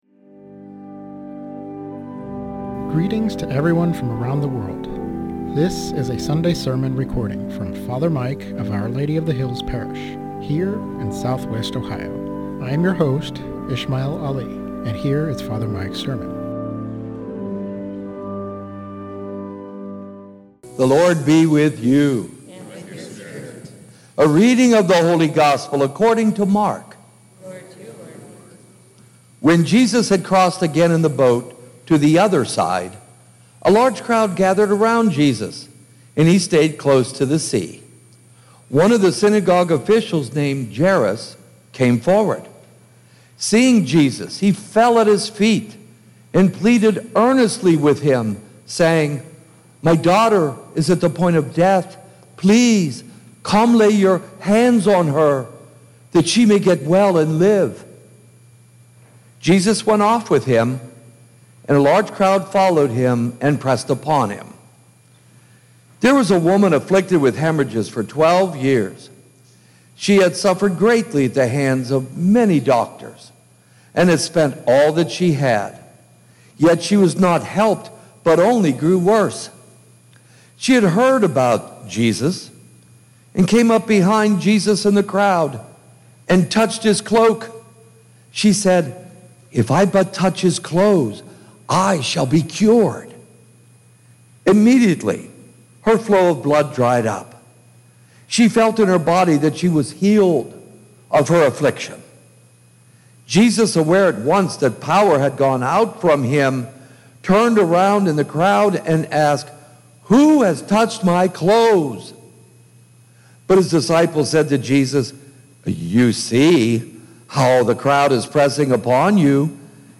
SERMON ON MARK 5:21-43